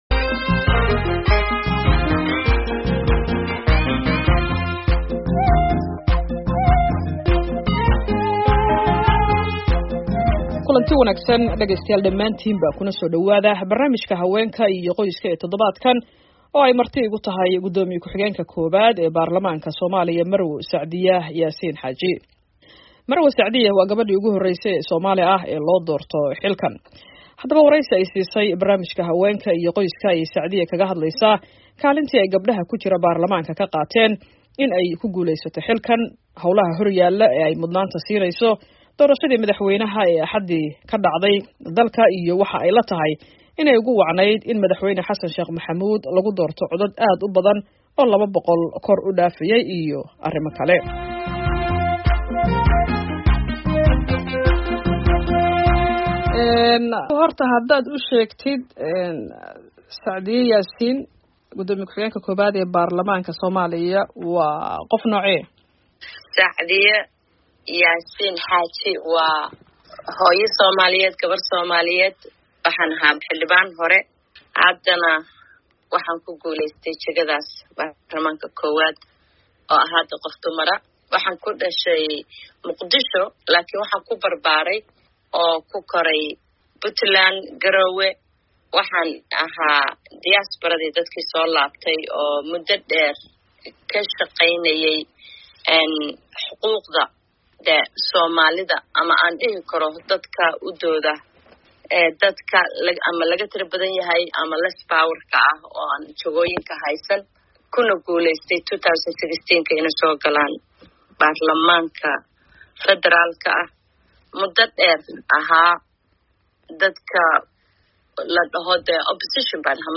Barnaamijka Haweenka iyo Arrimaha Qoyska: Wareysiga Sacdiya Yaasiin Xaaji
Barnaamijka Haweenka iyo Qoyska ee toddobaadkan waxaa marti ku ah guddoomiye ku-xigeenka koowaad ee golaha shacabka baarlamaanka Soomaaliya Marwo Sacdiya Yaasiin Xaaji. Marwo Sacdiya waa gabadhii ugu horreysay ee Soomaali ah ee loo doorto xilkan.